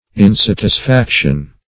Search Result for " insatisfaction" : The Collaborative International Dictionary of English v.0.48: Insatisfaction \In*sat`is*fac"tion\, n. 1.